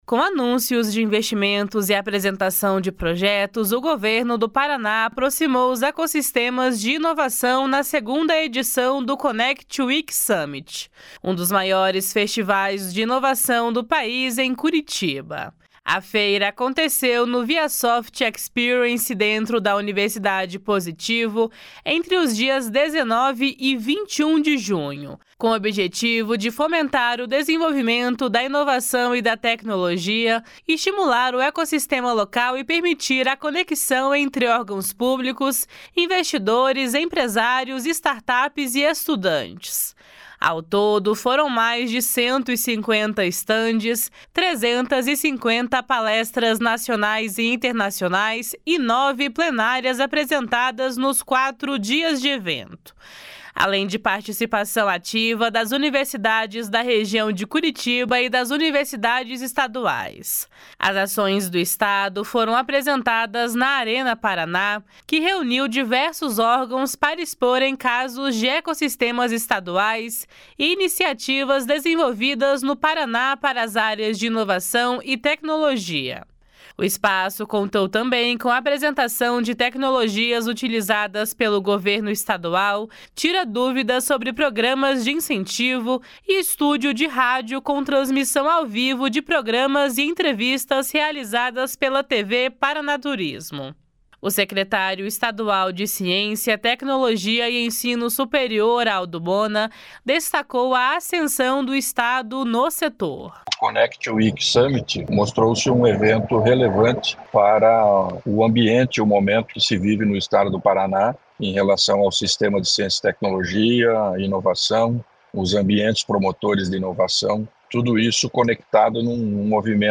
O secretário estadual de Ciência, Tecnologia e Ensino Superior, Aldo Bona, destacou a ascensão do Estado no setor. // SONORA ALDO BONA //